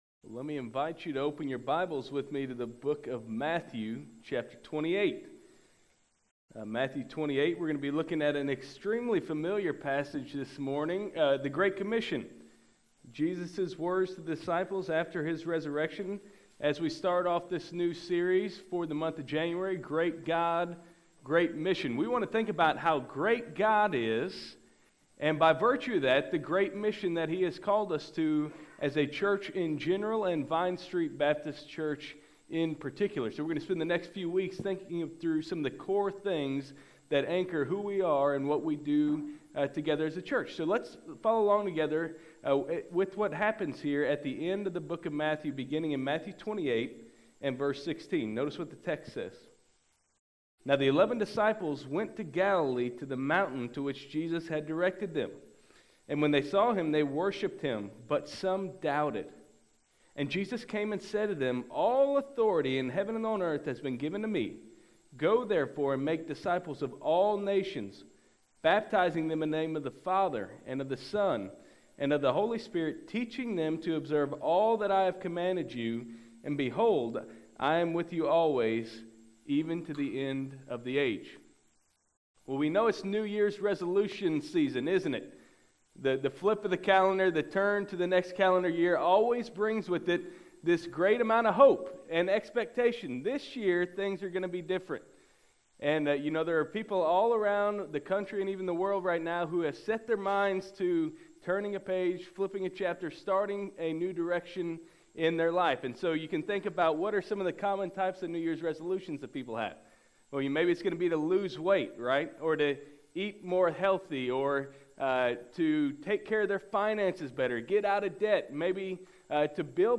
January 6, 2013 AM Worship | Vine Street Baptist Church